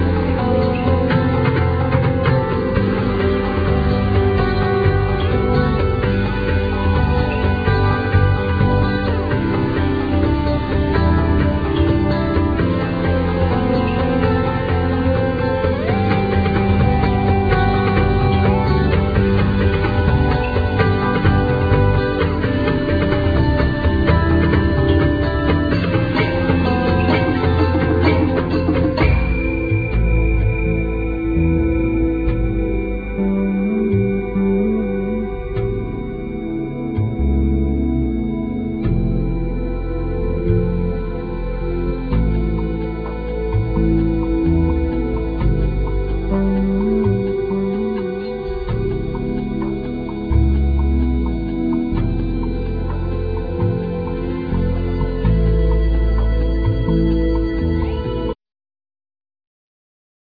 Piano,Keyboards,Programming
Guitar
Bass
Vocals